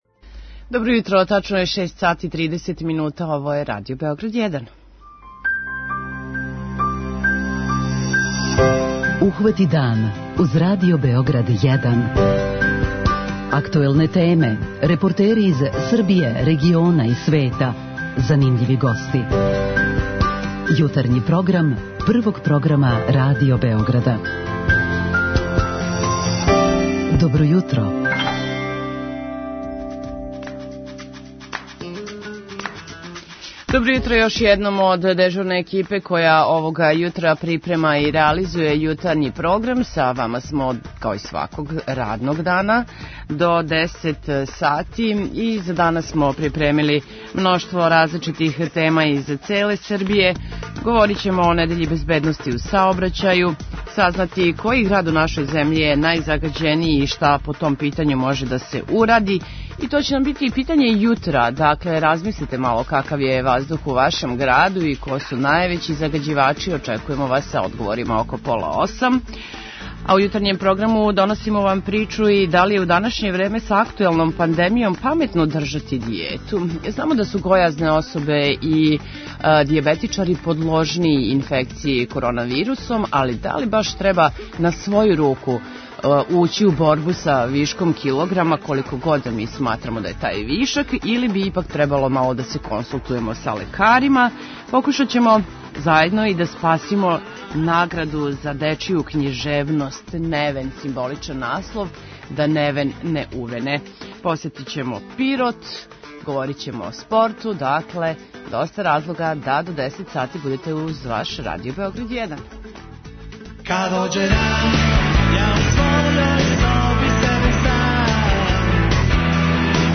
О томе разговарамо са Филипом Радовићем, директором Агенције за заштиту животне средине.